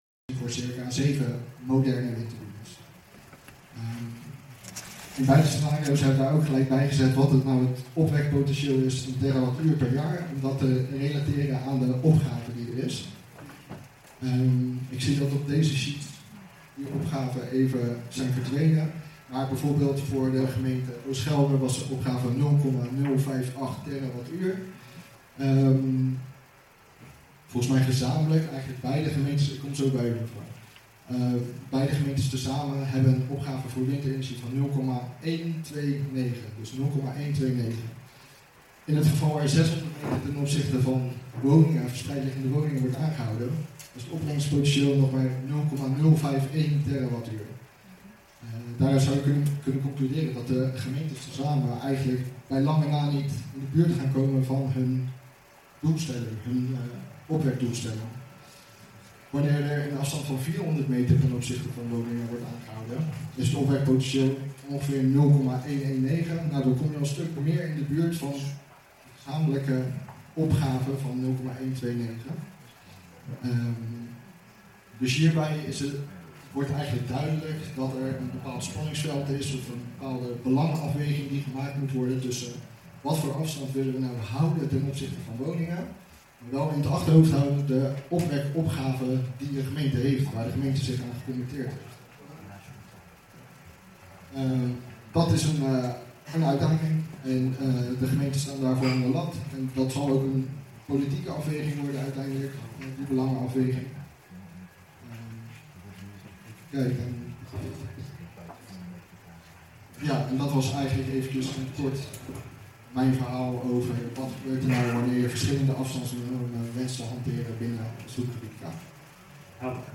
Op dinsdag 21 juni & donderdag 23 juni 2022 waren windcafés bij Erve Kots Lievelde.